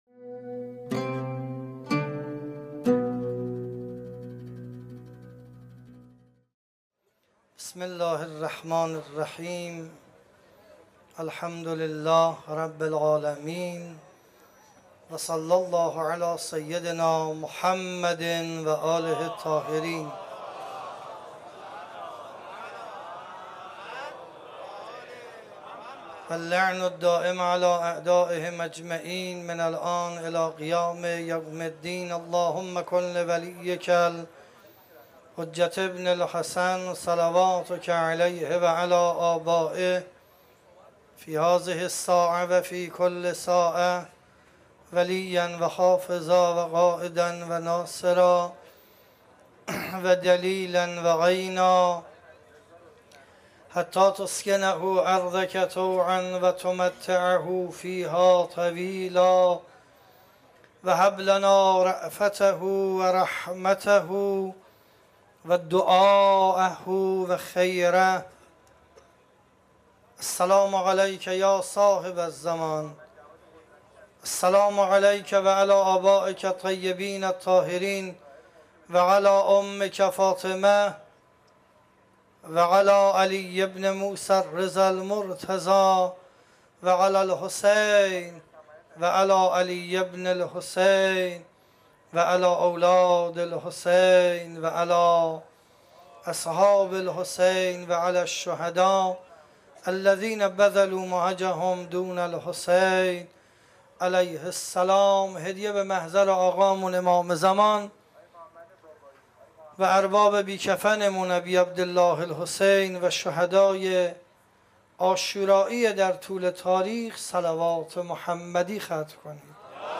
سخنرانی
اجتماع زائران ایرانی | اربعین سال 1394